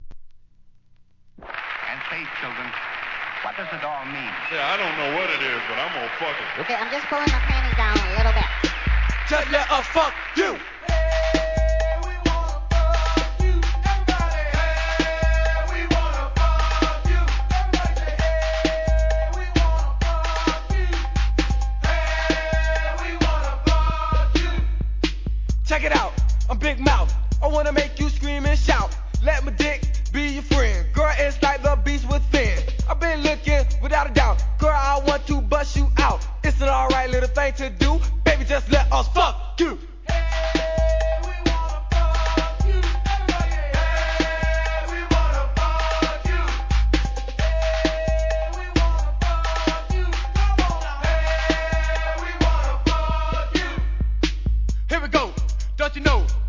HIP HOP/R&B
1988年、マイアミ産の強烈CLASSIC!!!